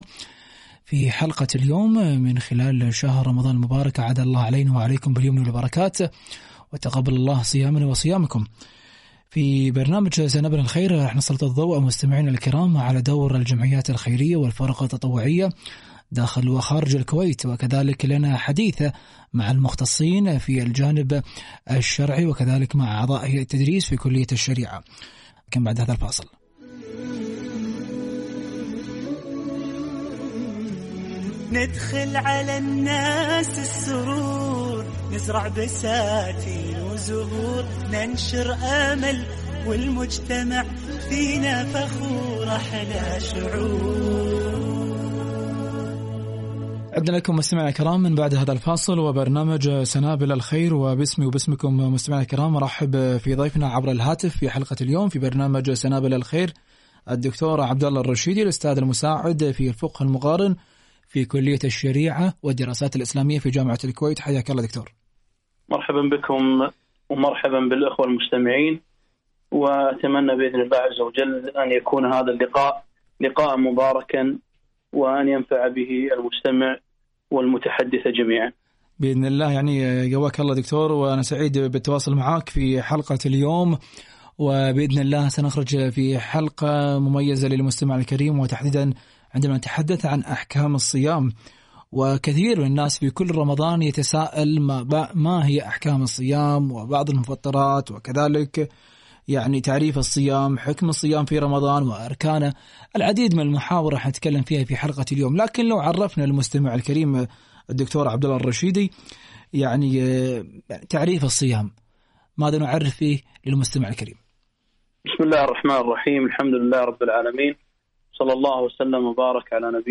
لقاء إذاعي - أحكام الصيام